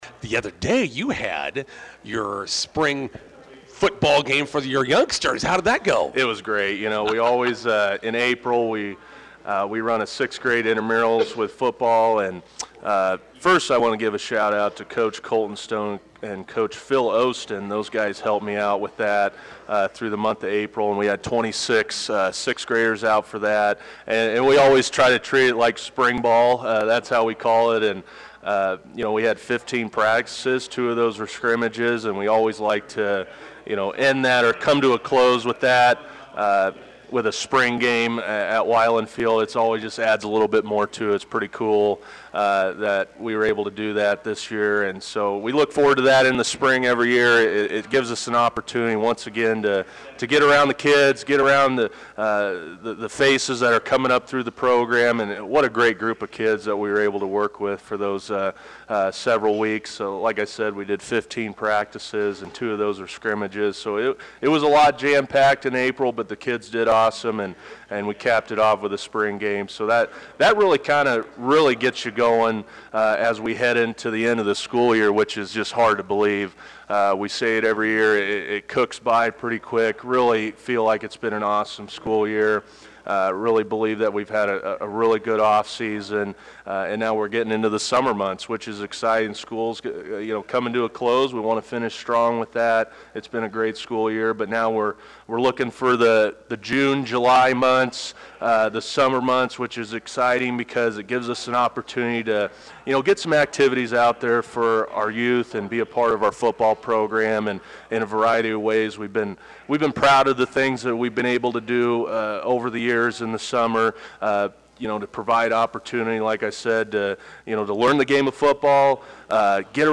INTERVIEW: Bison football activities picking up after the end of school.